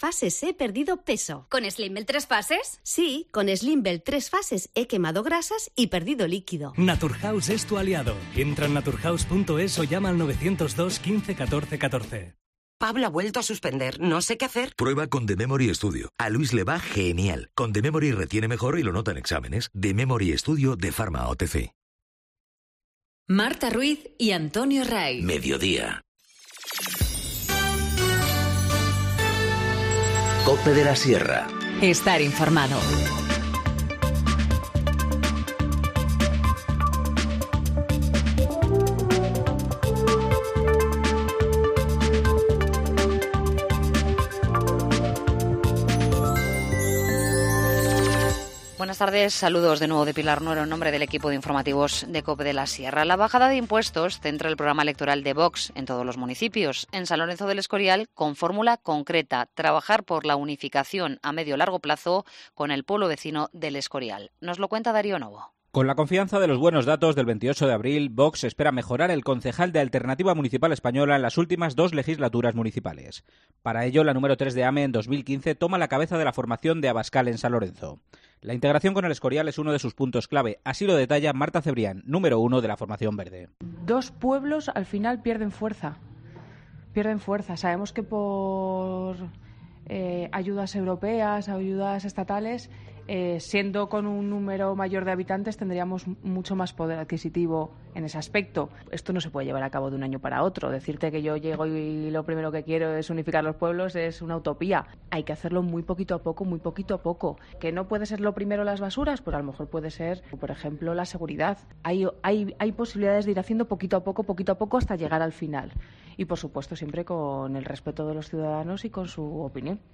Informativo Mediodía 23 mayo 14:50h